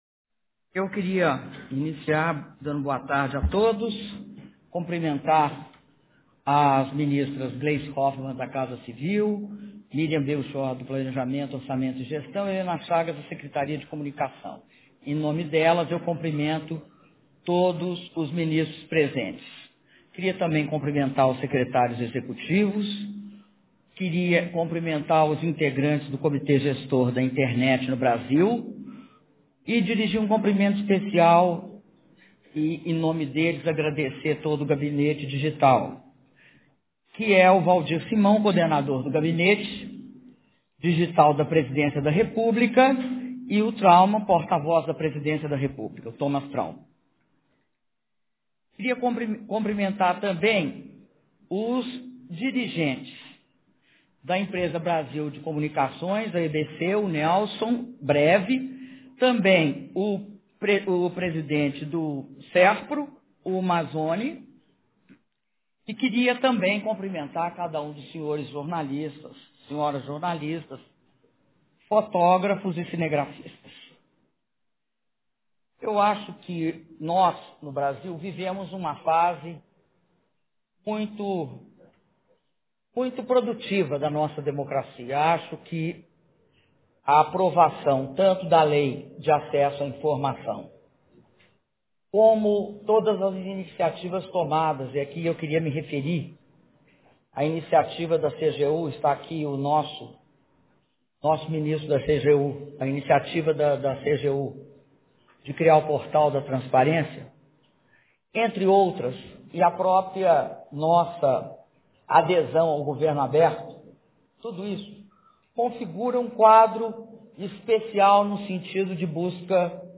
Áudio do discurso da Presidenta Dilma Rousseff durante apresentação do novo Portal Brasil (11min49s)
Discursos